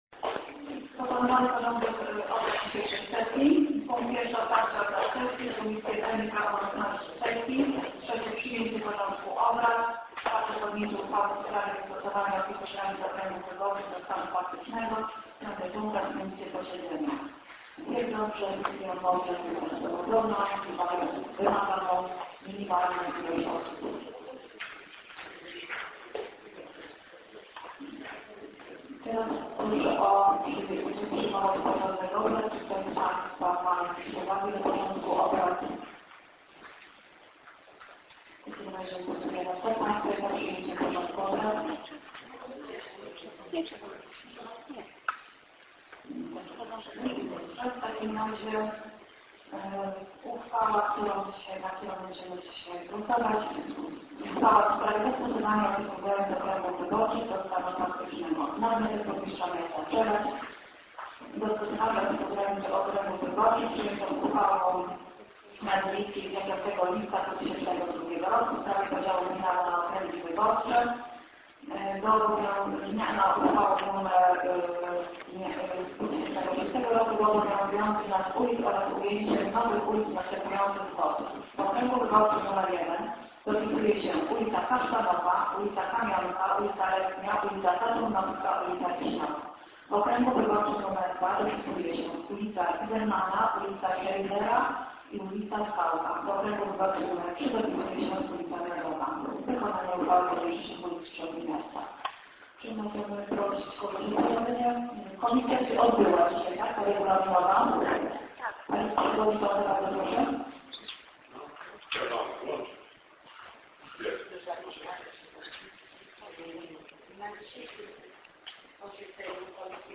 plik dzwiękowy z sesji 12 sierpnia 2010